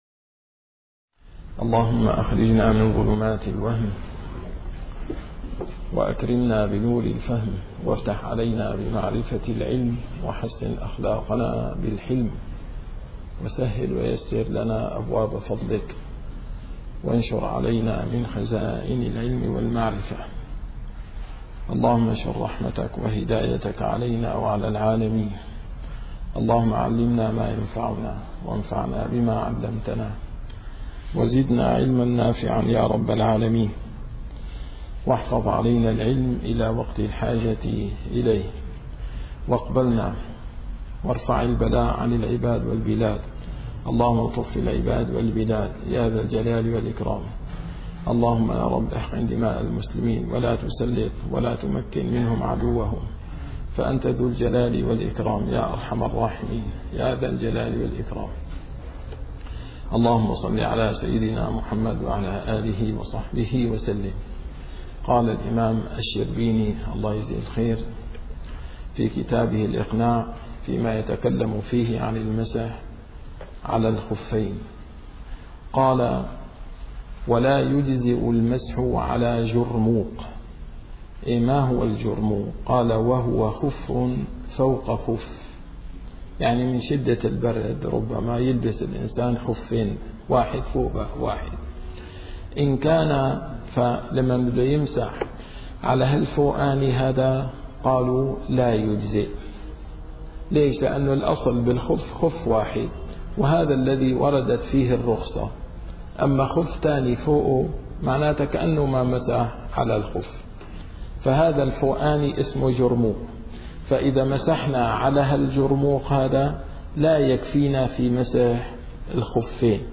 - الدروس العلمية - الفقه الشافعي - كتاب الإقناع - ولا يجزئ المسح على جرموق ص 65 إلى ص 66